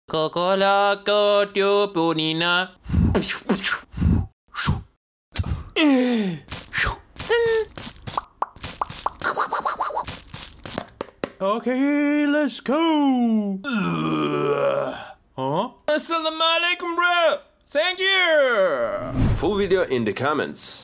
Sound effects of [MINECRAFT] 😳 sound effects free download